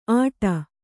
♪ āṭa